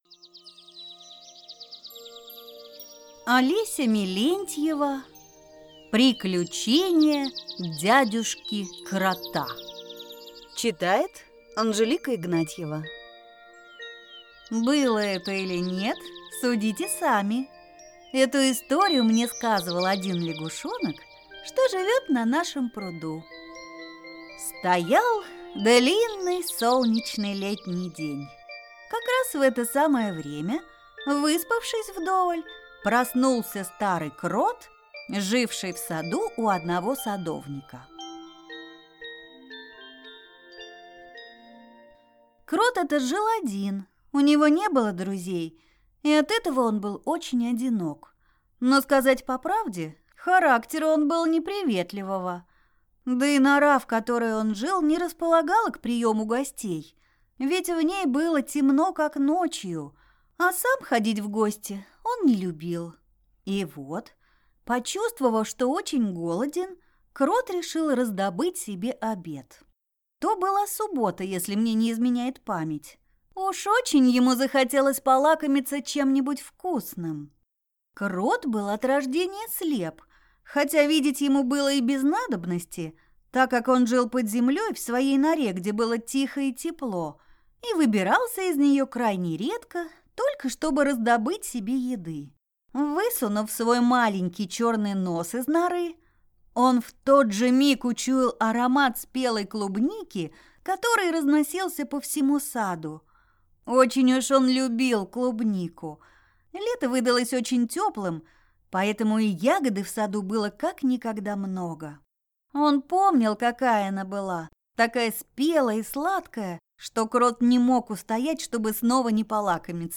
Аудиокнига Приключение дядюшки Крота | Библиотека аудиокниг